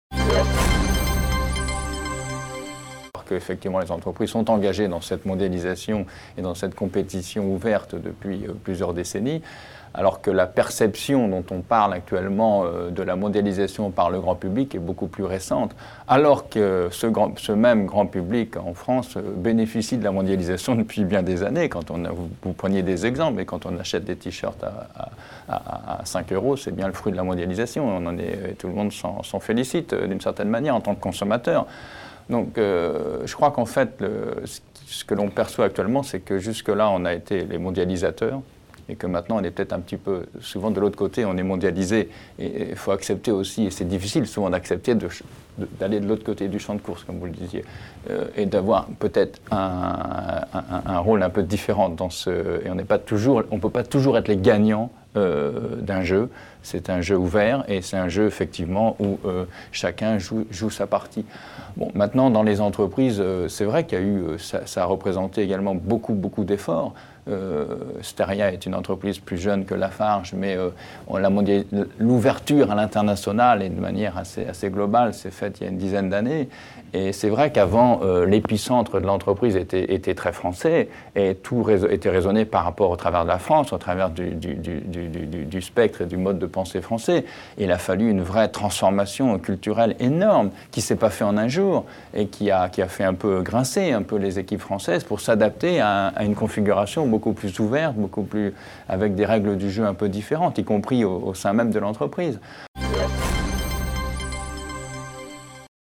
Extraits du débat économique consacré à l'impact de la Mondialisation sur les entreprises et les salariés